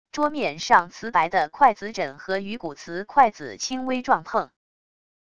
桌面上瓷白的筷子枕和鱼骨瓷筷子轻微撞碰wav音频